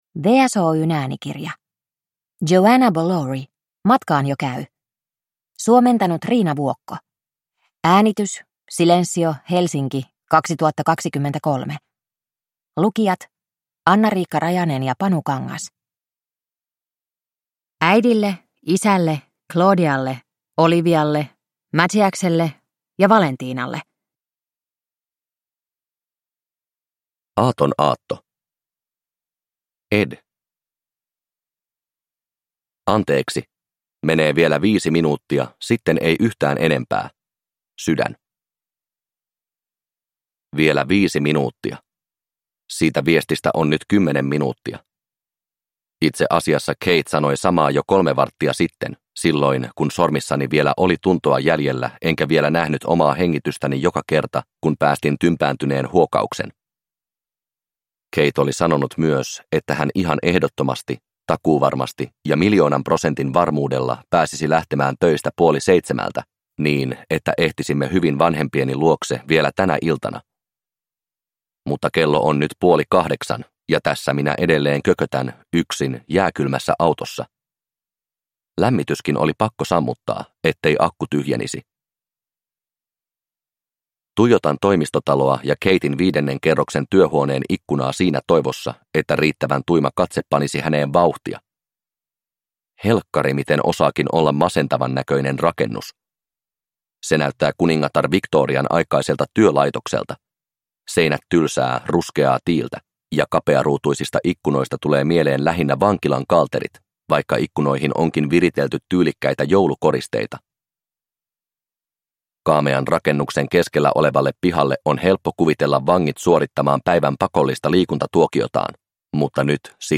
Matkaan jo käy – Ljudbok – Laddas ner